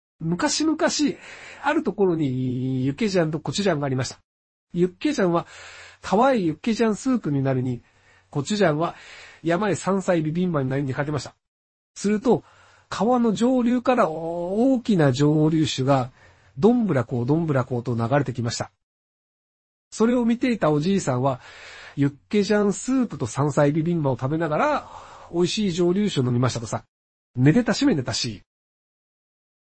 下図の、赤枠で囲んだ部分をクリックすると、ひろゆきの声で文章を読んでくれます。
作成したAI音声データがこちら！